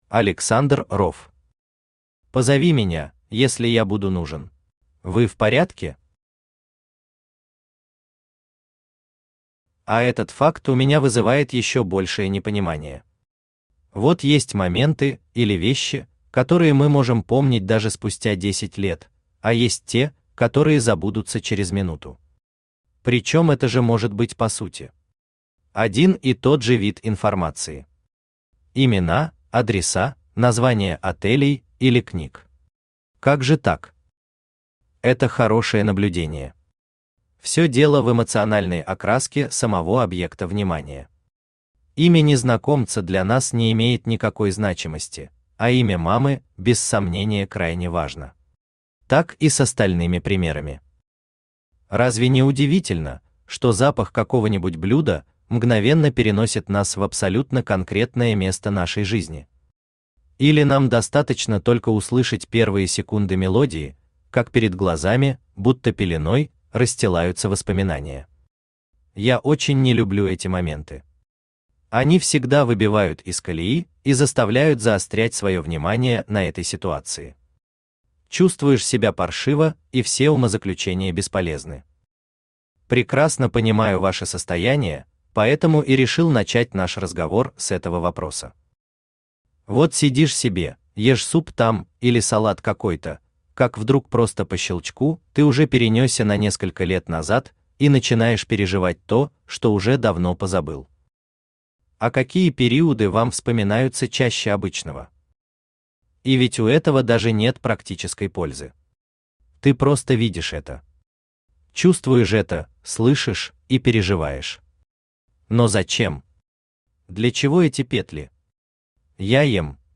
Аудиокнига Позови меня, если я буду нужен | Библиотека аудиокниг
Aудиокнига Позови меня, если я буду нужен Автор Александр Ров Читает аудиокнигу Авточтец ЛитРес.